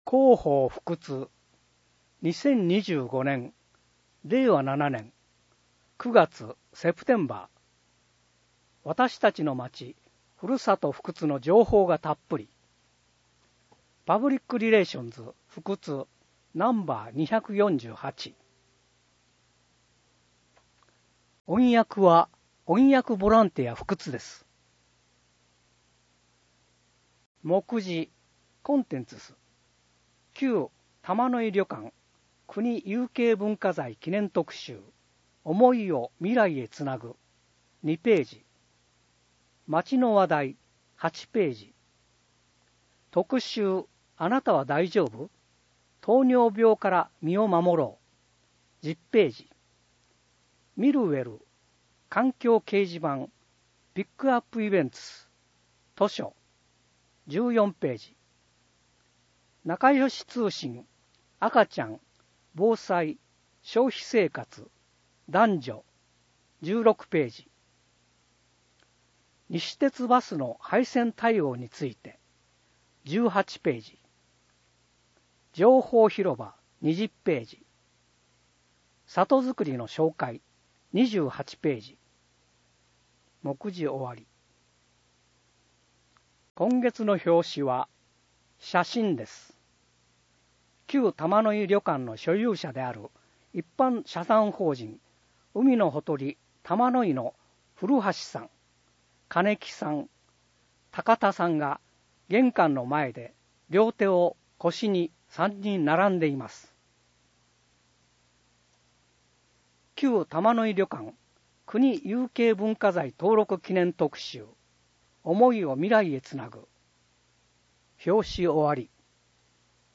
音訳ボランティアふくつの皆さんが、毎号、広報ふくつを音訳してくれています。